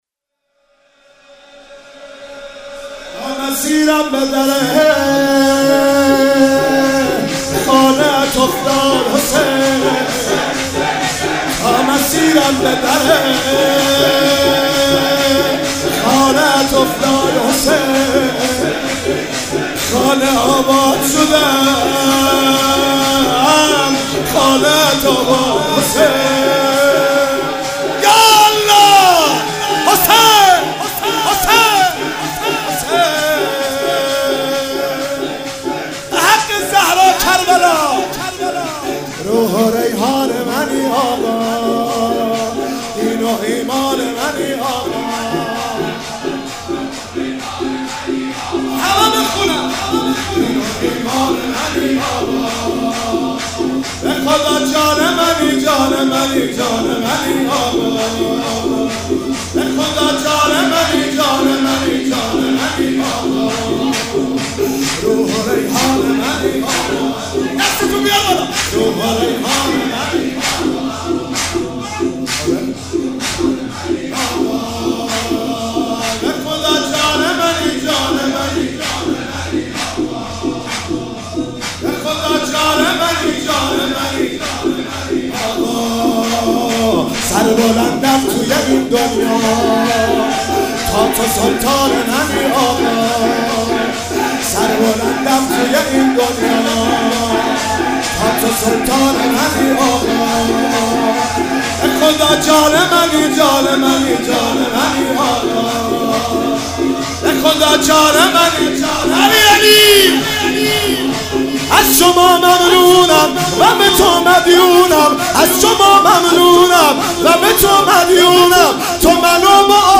مناسبت : شب بیست و دوم رمضان
قالب : شور